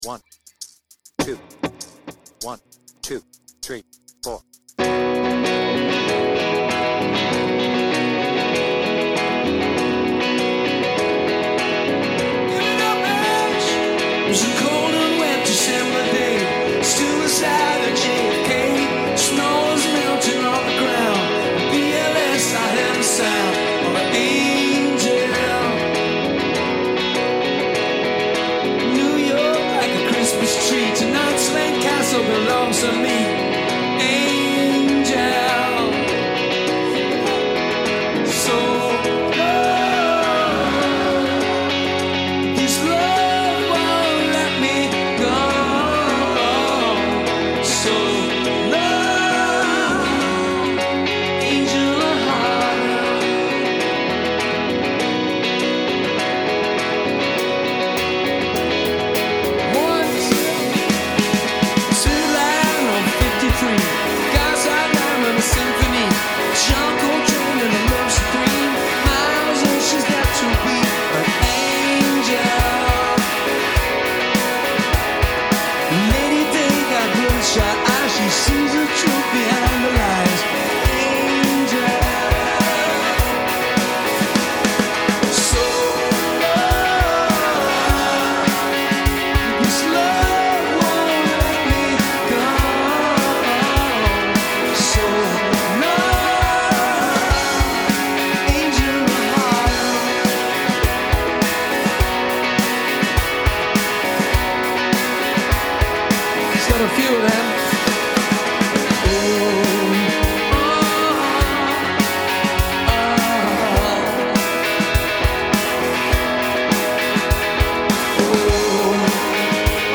BPM : 83
Tuning : E
With Vocals